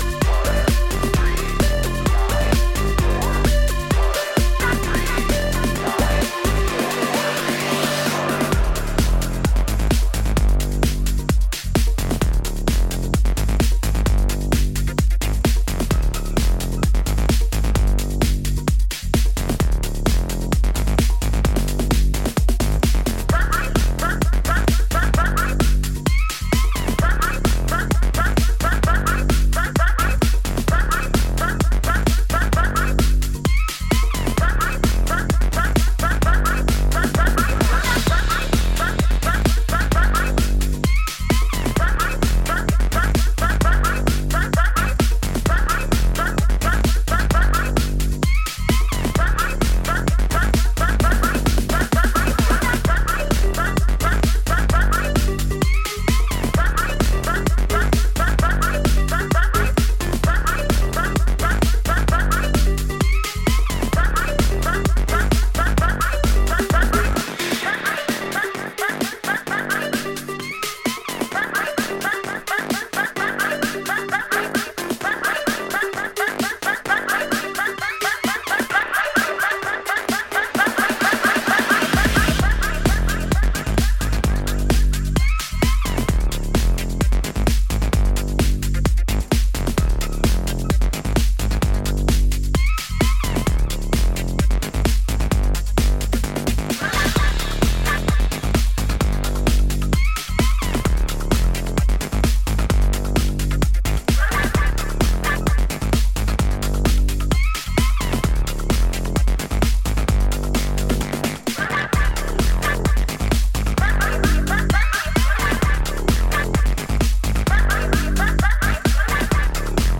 レトロフューチャーな響きのシンセリフがピークタイムを煽る